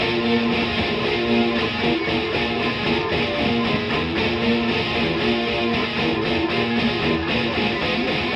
跟进 115bpm Ais
Tag: 115 bpm Dubstep Loops Guitar Electric Loops 1.41 MB wav Key : A